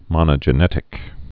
(mŏnə-jə-nĕtĭk)